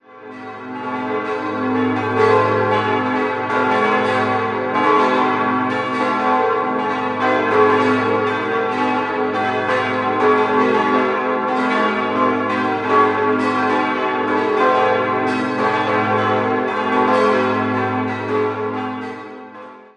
Das reformierte Gemeindezentrum wurde in Jahren 1957 bis 1959 errichtet. 6-stimmiges Geläute: a°-c'-e'-g'-a'-h' Die Glocken 2 bis 6 wurden 1959 von der Gießerei Rüetschi in Aarau gegossen, die große stammt aus dem Jahr 1961.